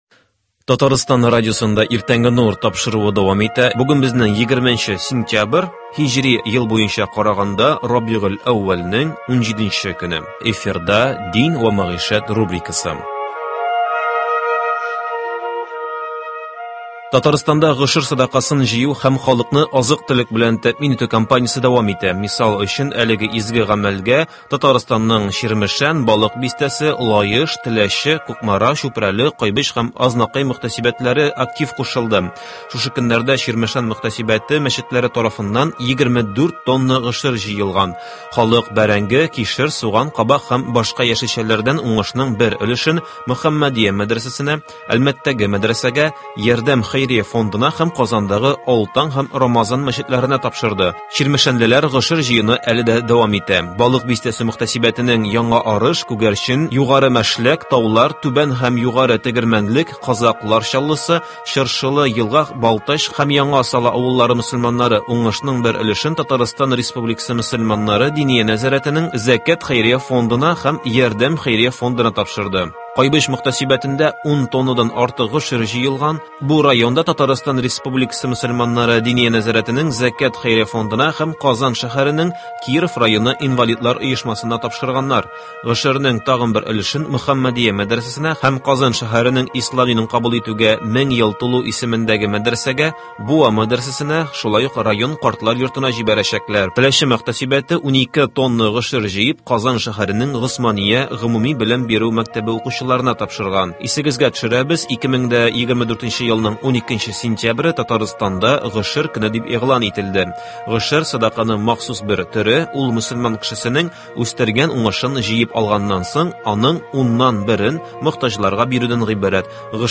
Аның белән 3 нче әңгәмә тәкъдим итәбез.